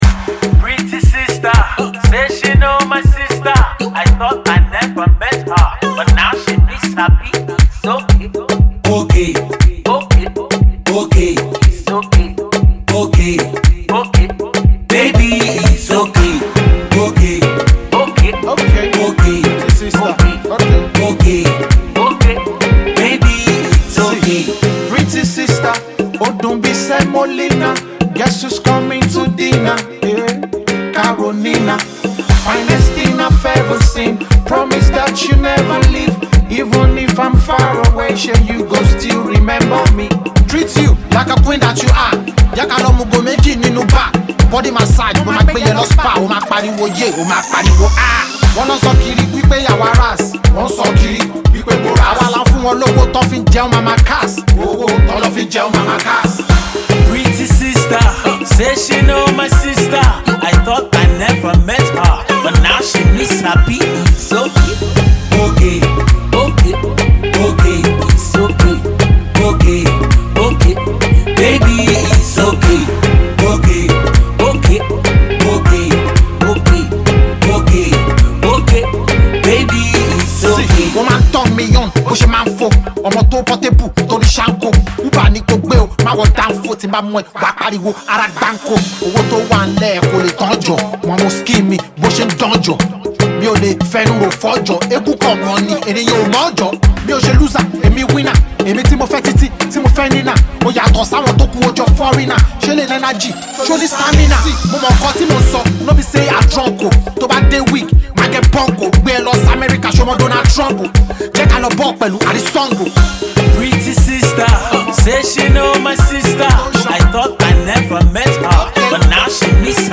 club banger
will keep you On the dance floor